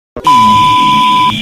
ee-error.mp3